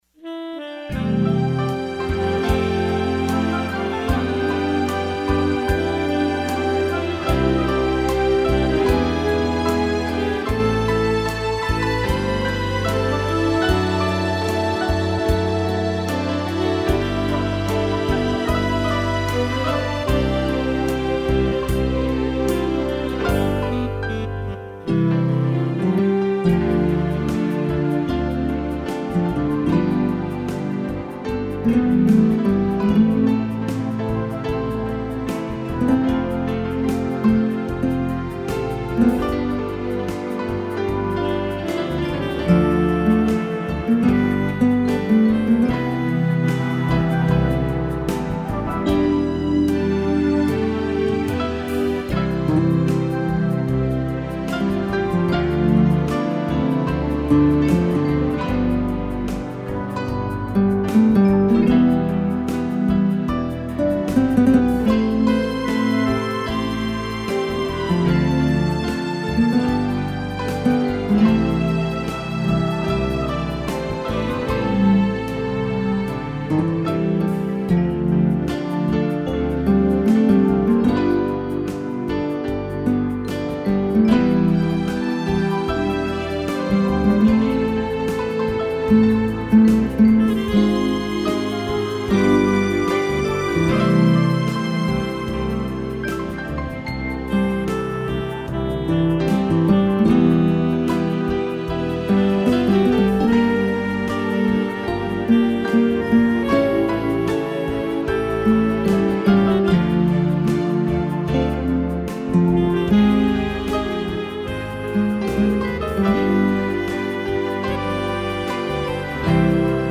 piano, sax e strings
(instrumental)